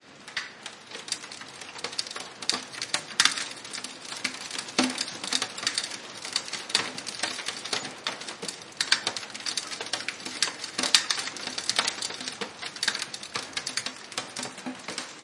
雷霆风暴
描述：我从我的一些录音中创造了关于Logic的雷暴。
Tag: 暴风雨 天气 环境 自然